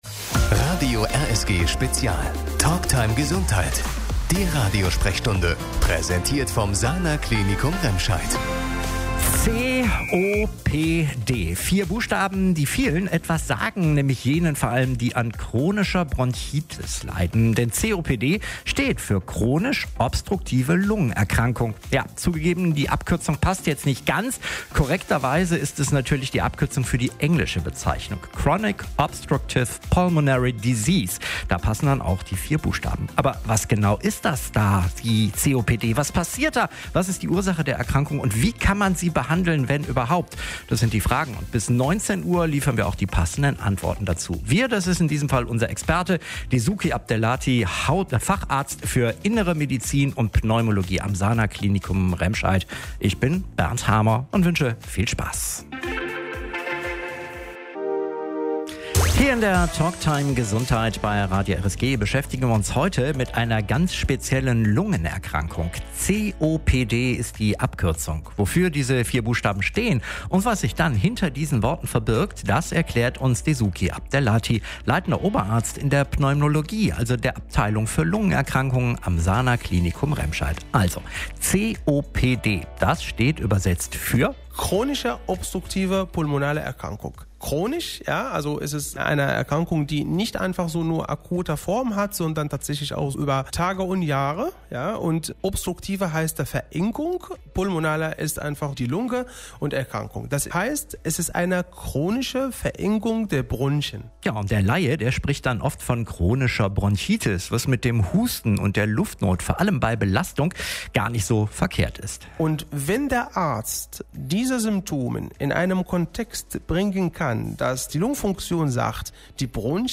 Bekommt jeder Raucher irgendwann eine chronische Lungenerkrankung? Das war unser Thema in der letzten Radiosprechstunde.